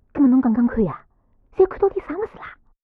c02_6偷听对话_李氏_2_fx.wav